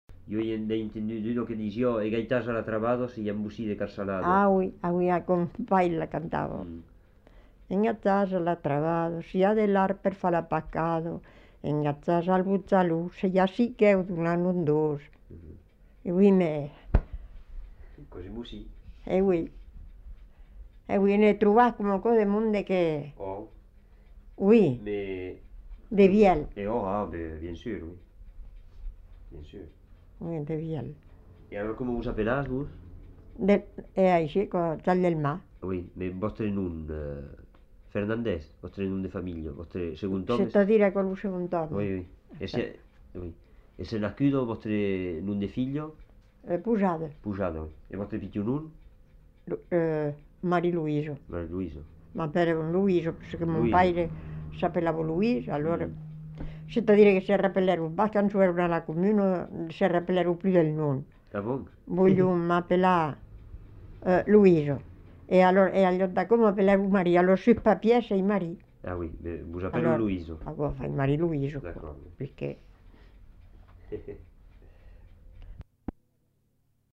Aire culturelle : Haut-Agenais
Lieu : Fumel
Genre : forme brève
Effectif : 1
Type de voix : voix de femme
Production du son : chanté
Classification : formulette